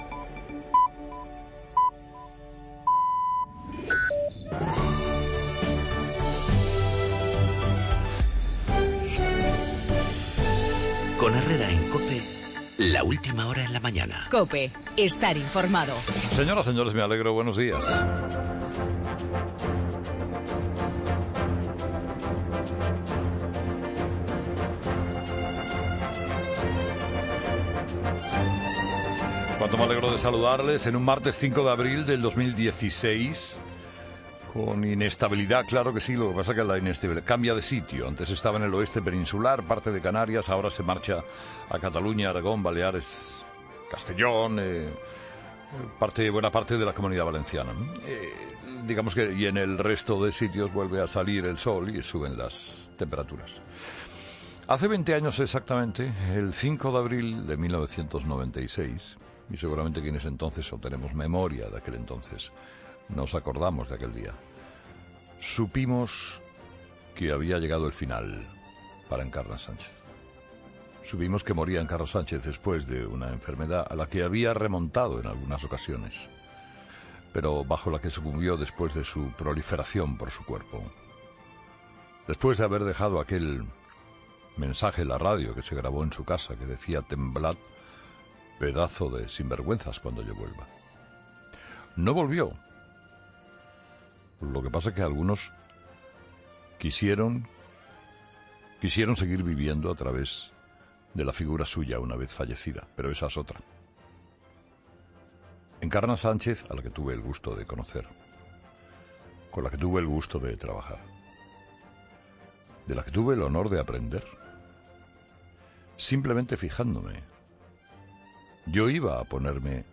"Encarna Sánchez era arroyadora, capaz de lo mejor y de lo peor, pero nadie sabe que fue de una generosidad secreta asombrosa", ha dicho Carlos Herrera de la histórica comunicadora de la Cadena COPE, en el editorial de Carlos Herrera a las 8 de la mañana, donde también ha hablado de la exclusiva del diario de ABC de la financiación de Venezuela a la fundación germen de Podemos con 7 millones de euros en 2008 y de los 'Papeles de Panamá'